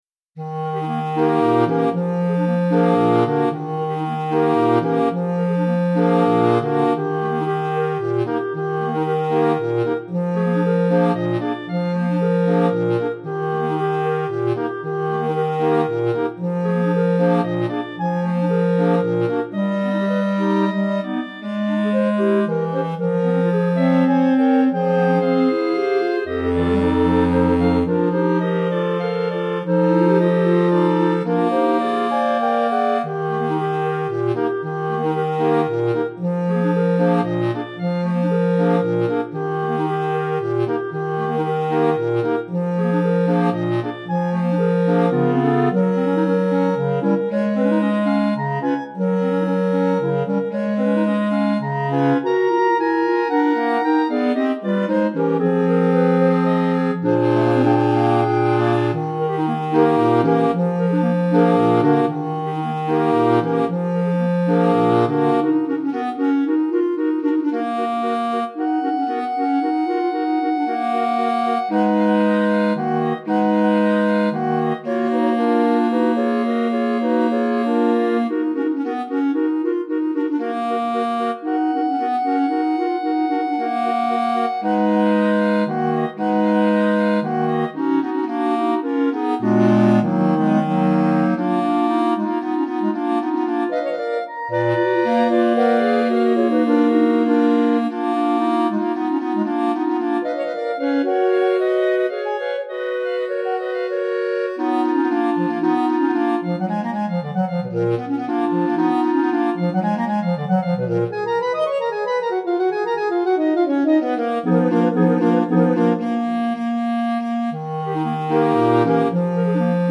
Répertoire pour Clarinette - 5 Clarinettes